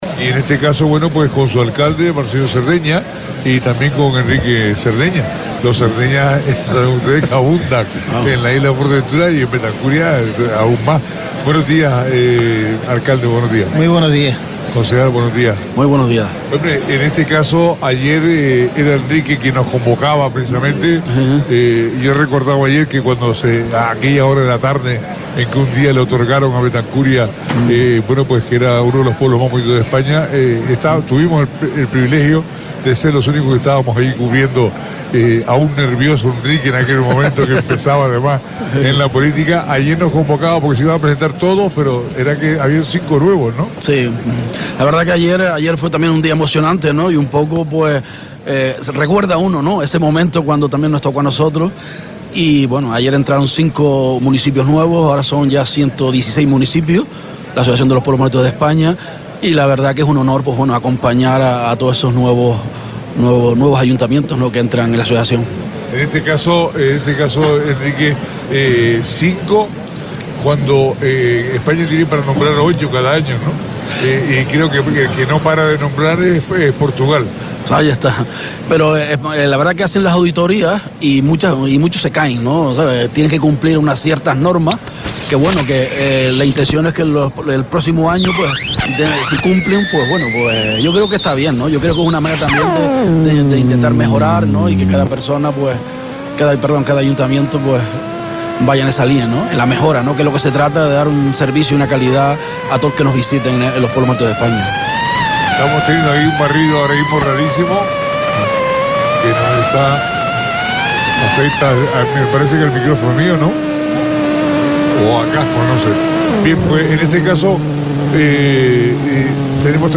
Entrevistas ¿Cómo ser uno de los Pueblos más bonitos de España?